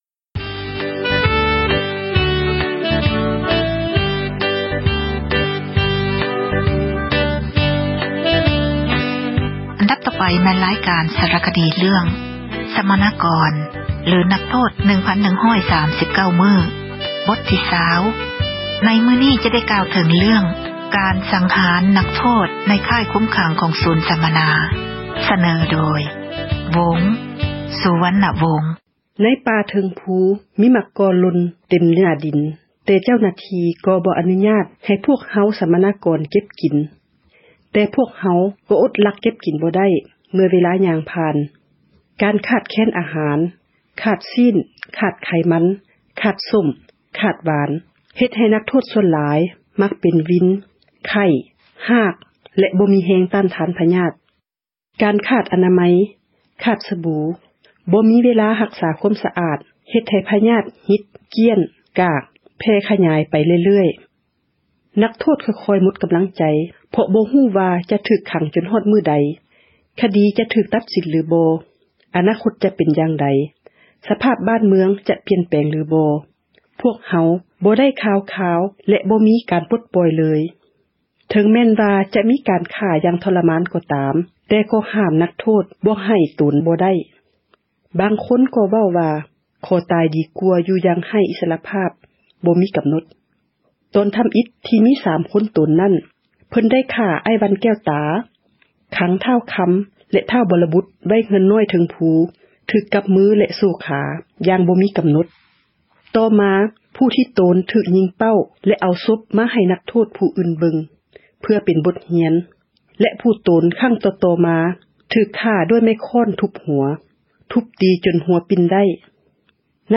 ສາຣະຄະດີ ເຣື້ອງ ສັມມະນາກອນ ຫຼື ນັກໂທດ 1139 ມື້, ບົດ ທີ 20 ໃນມື້ນີ້ ຈະກ່າວເຖິງ ການສັງຫານ ນັກໂທດ ໃນ ຄ້າຍຄຸມຂັງ ຂອງ ສູນ ສັມມະນາ.